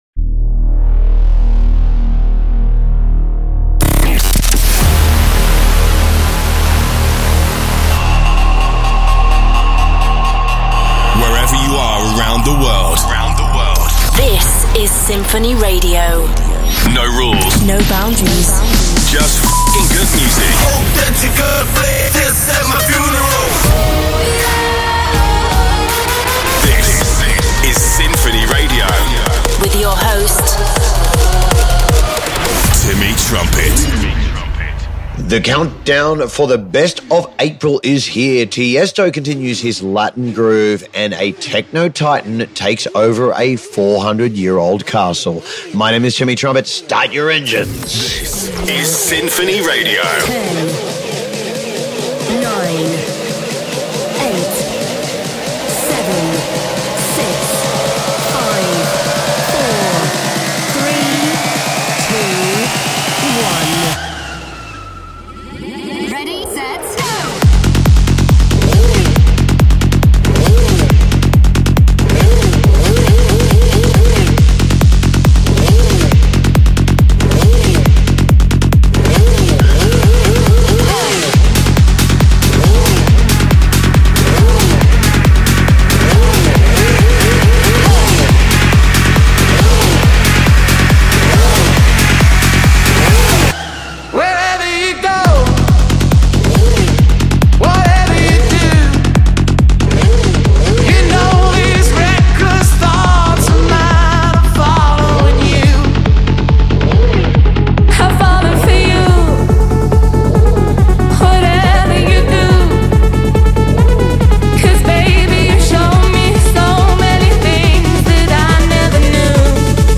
House mix